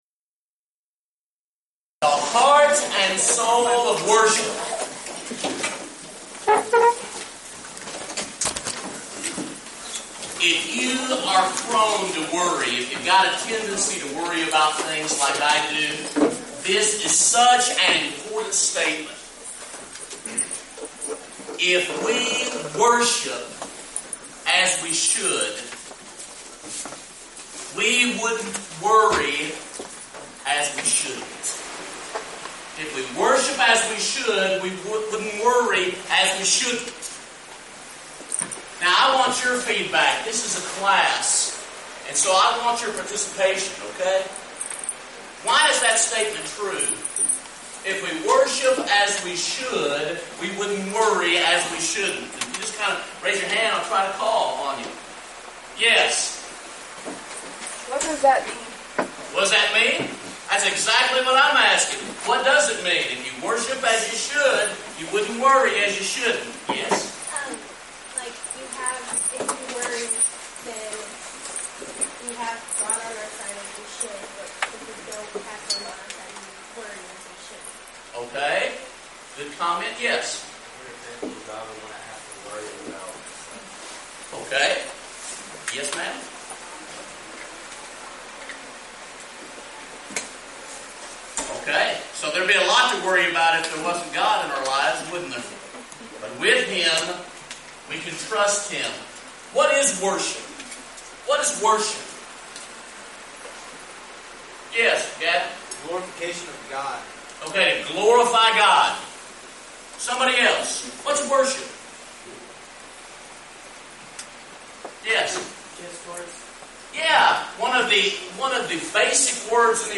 Title: Bible Class: The Heart and Soul of Worship
Event: 2015 Discipleship University Theme/Title: Soul Work: Putting Effort Into Your Eternity